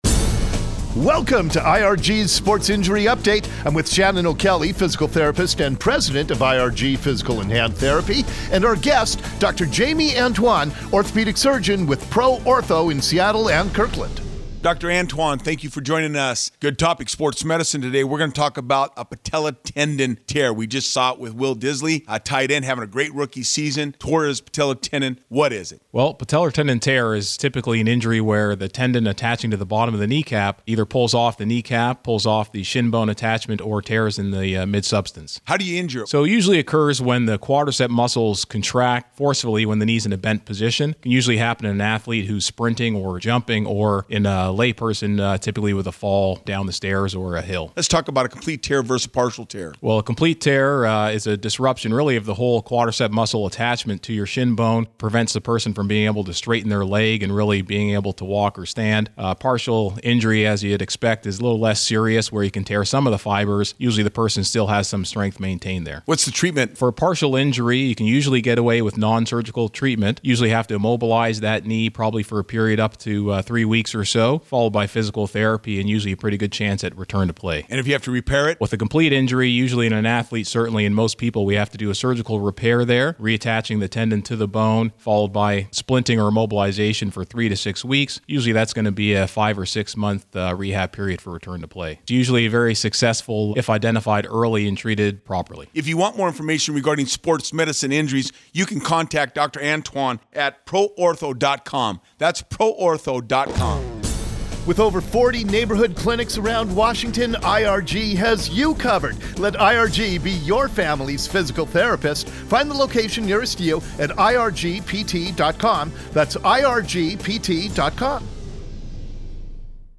IRG Sports Injury Update – Patellar Tendon Radio Segment: